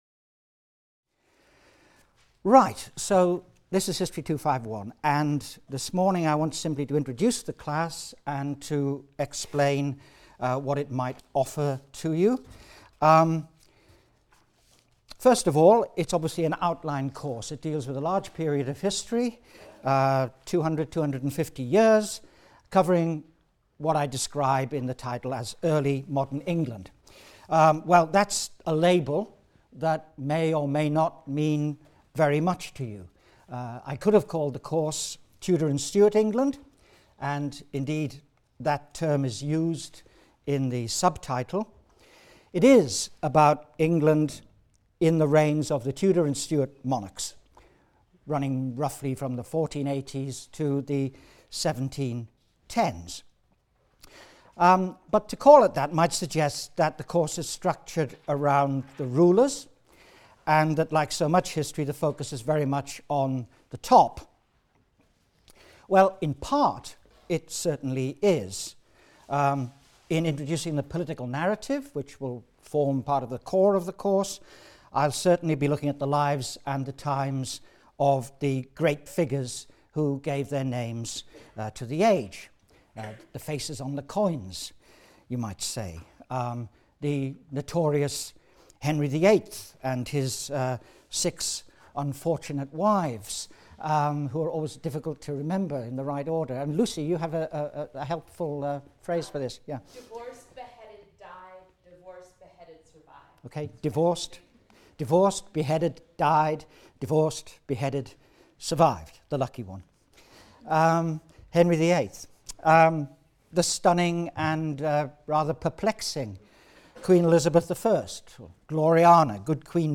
HIST 251 - Lecture 1 - General Introduction | Open Yale Courses